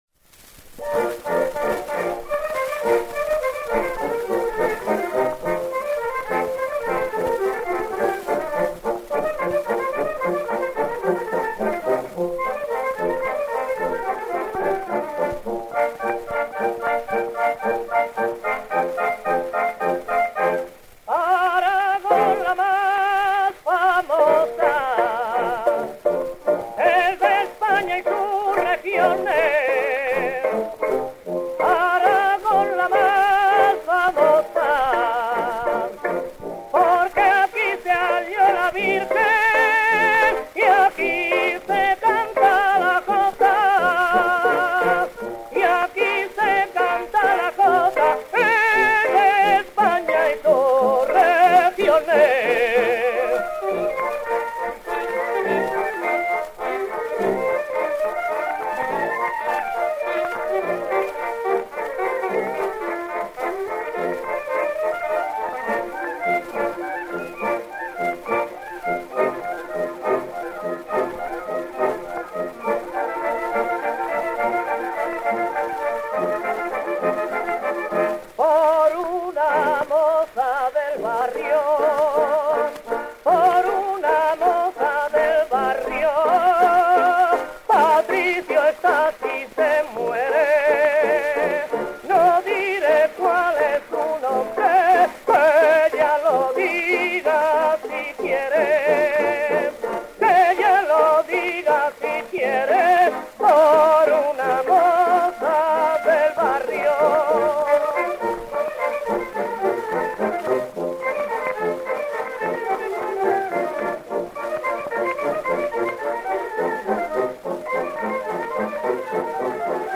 In any case, he studied voice at the Madrid conservatory with baritone Napoleón Bergés, and was originally an opera tenor.
From 1905/06, when he was a member of the itinerant Gorgé company, he dedicated himself exclusively to zarzuela, and sang with all the leading companies in that genre.